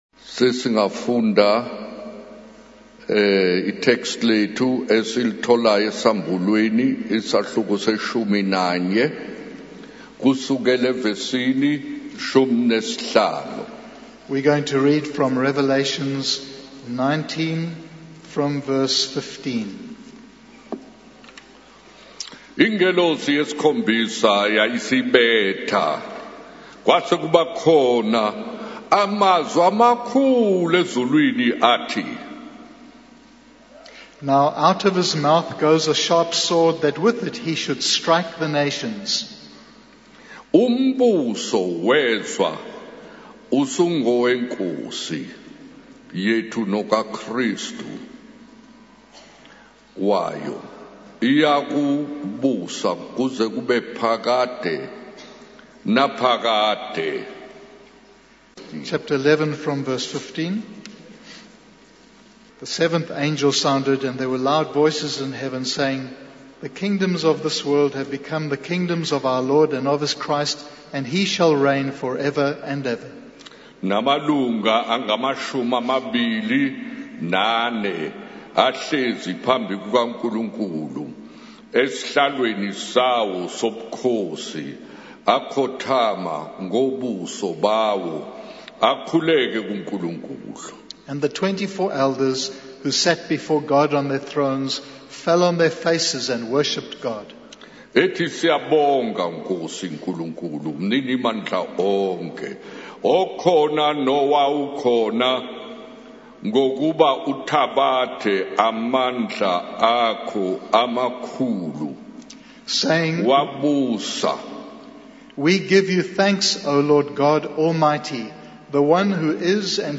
In this sermon, the preacher shares a story about a young man who went to say goodbye to someone in Pretoria.